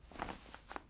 ar_mag_store.ogg